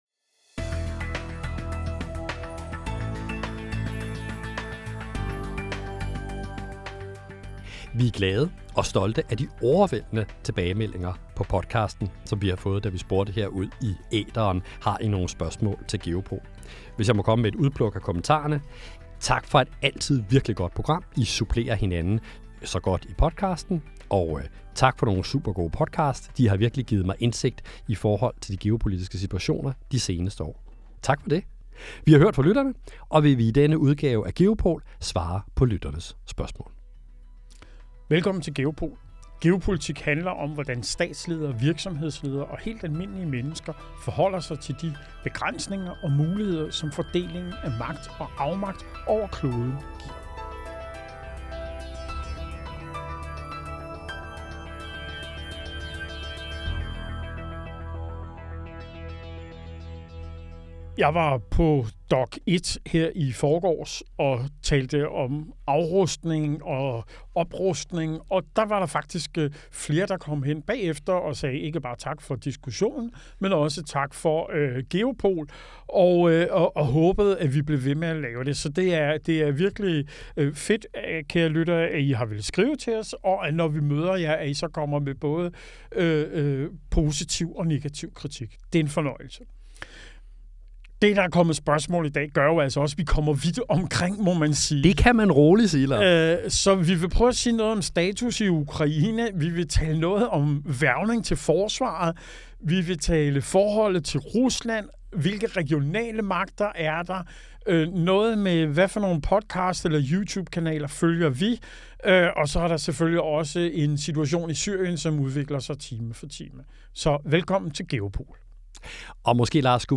I denne særudgave af Geopol inviterer vi lytterne indenfor i podcaststudiet.